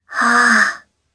Kara-Vox_Casting3_jp.wav